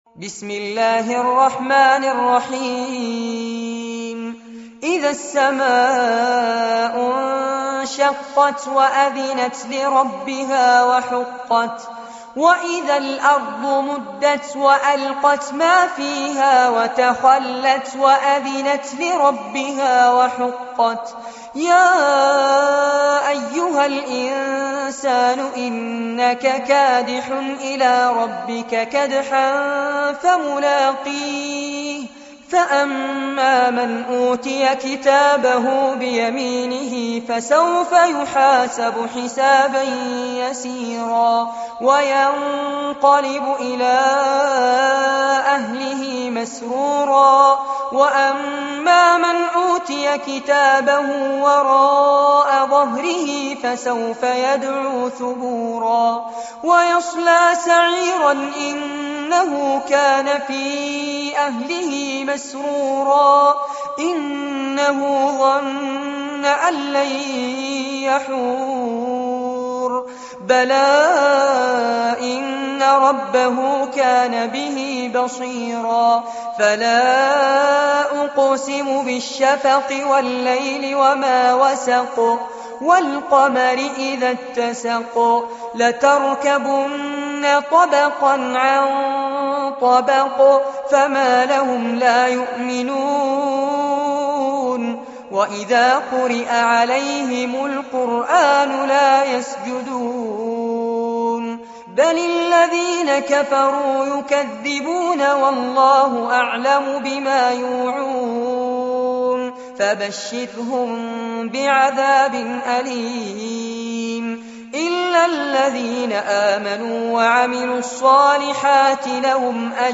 عنوان المادة سورة الإنشقاق- المصحف المرتل كاملاً لفضيلة الشيخ فارس عباد جودة عالية